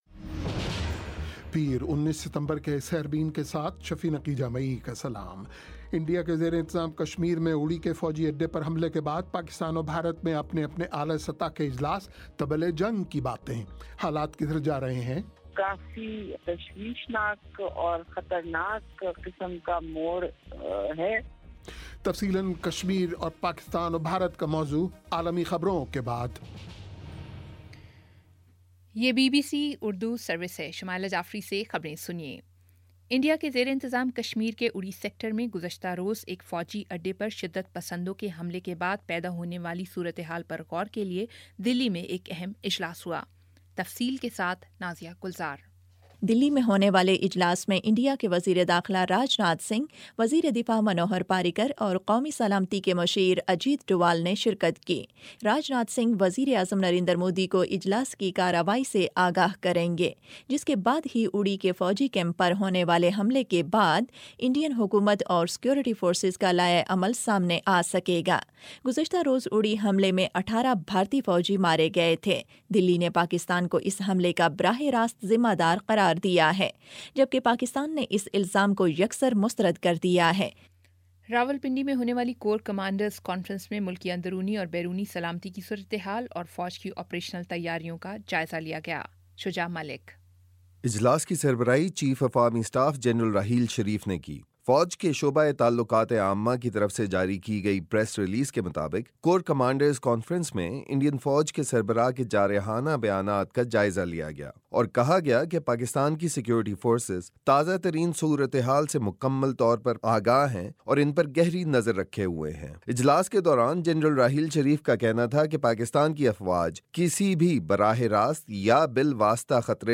پیر 19 ستمبر کا سیربین ریڈیو پروگرام